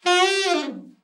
ALT FALL  12.wav